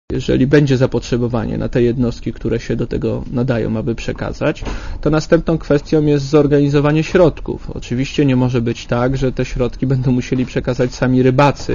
Jak dowiedziało się Radio ZET do ustalenia pozostaje jeszcze ile takich kutrów polscy rybacy będą mogli przekazać i kto zapłaci za transport - mówi minister rolnictwa Wojciech Olejniczak.
Komentarz audio